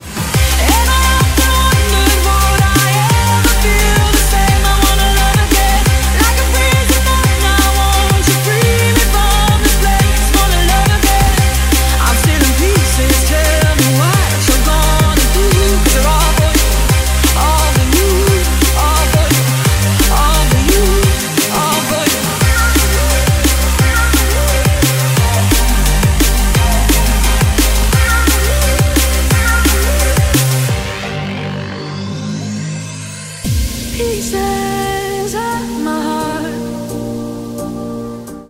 • Качество: 128, Stereo
красивый женский голос
быстрые
drum&bass
Стиль: drum & bass.